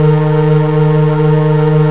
liftloop.mp3